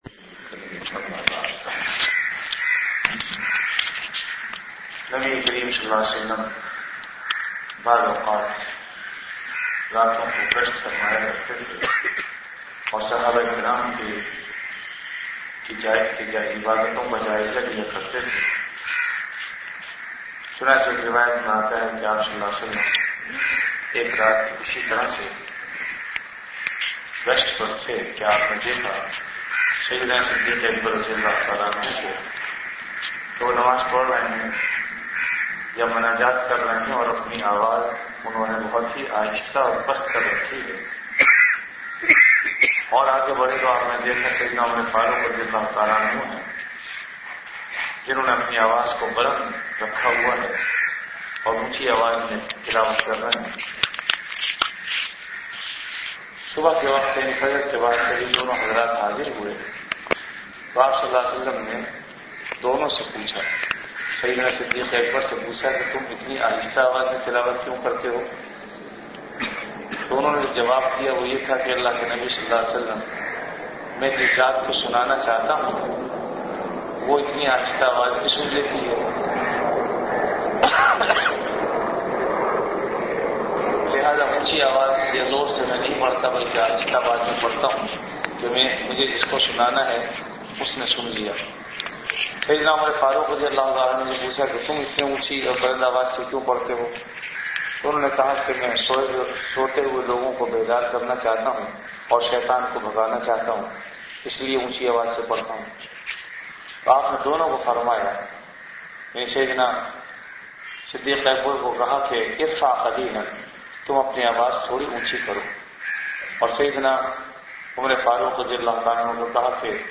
Delivered at Jamia Masjid Bait-ul-Mukkaram, Karachi.
Ramadan - Dars-e-Hadees · Jamia Masjid Bait-ul-Mukkaram, Karachi